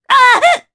Naila-Vox_Damage_jp_03.wav